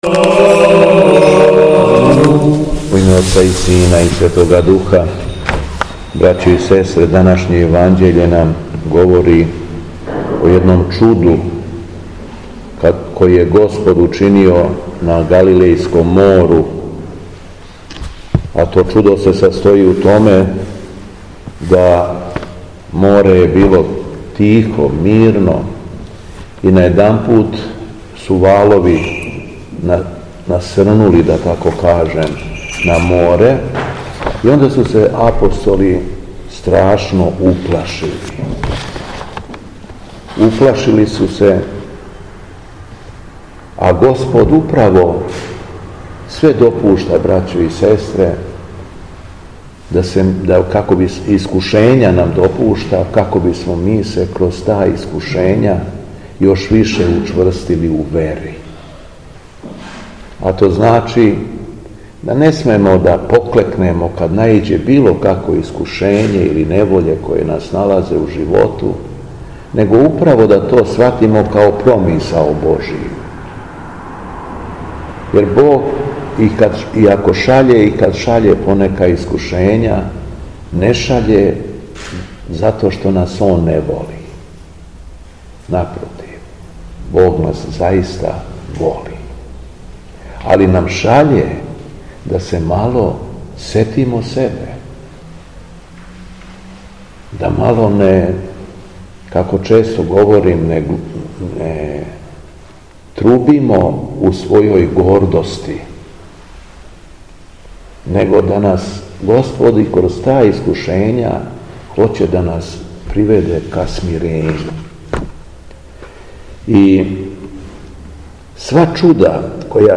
Беседа Његовог Преосвештенства Епископа шумадијског г. Јована
Епископ Јован је одржао беседу након прочитаног Јеванђеља по Марку: